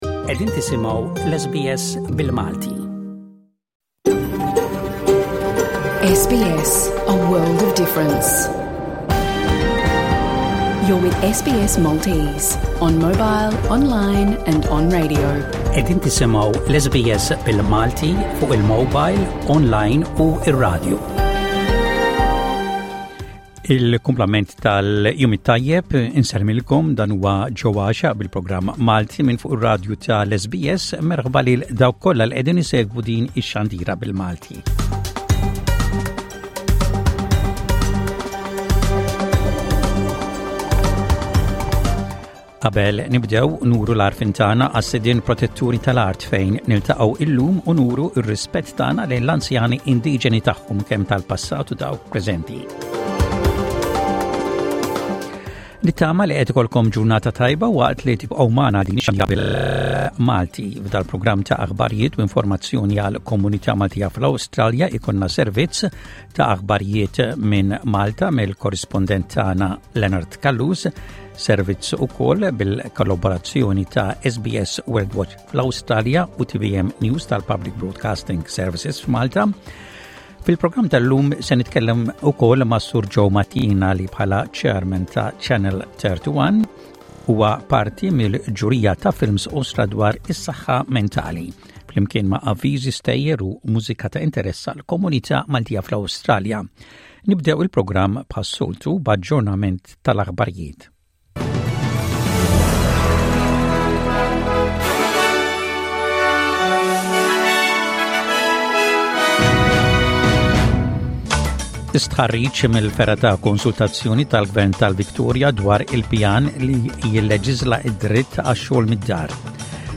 Avviżi komunitarji.